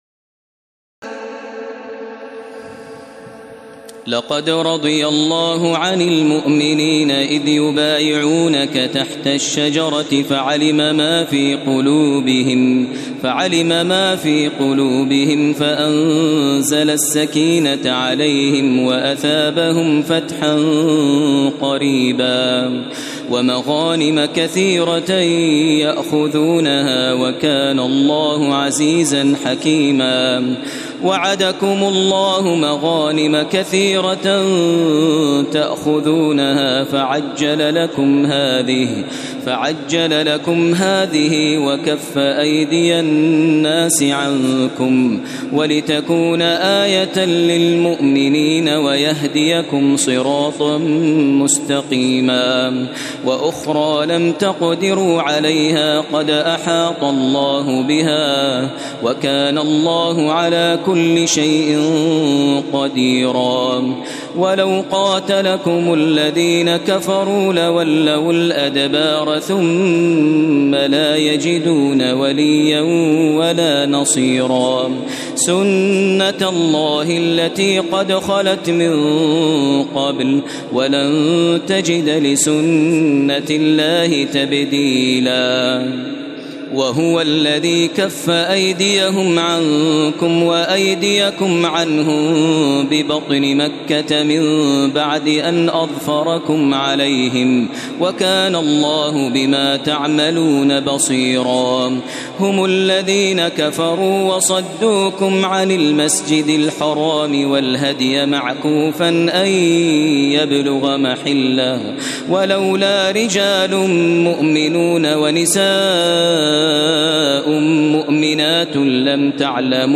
تراويح ليلة 25 رمضان 1432هـ من سور الفتح (18-29) الحجرات و ق و الذاريات (1-37) Taraweeh 25 st night Ramadan 1432H from Surah Al-Fath and Al-Hujuraat and Qaaf and Adh-Dhaariyat > تراويح الحرم المكي عام 1432 🕋 > التراويح - تلاوات الحرمين